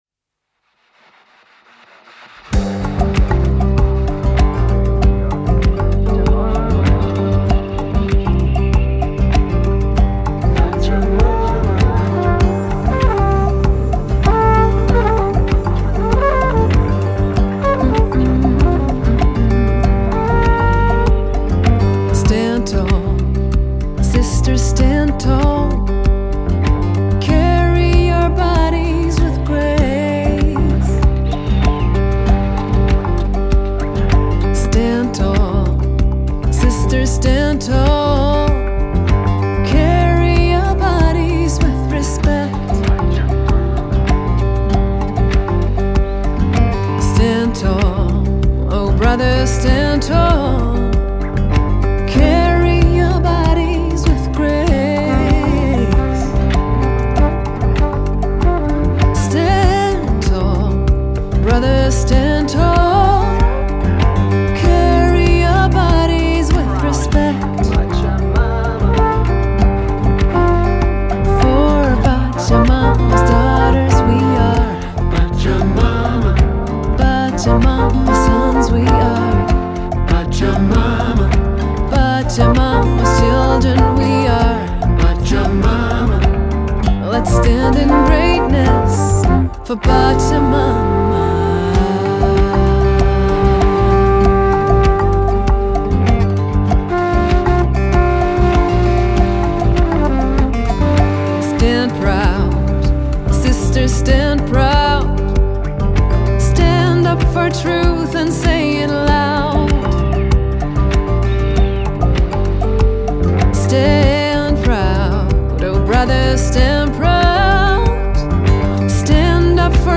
the art of improvisation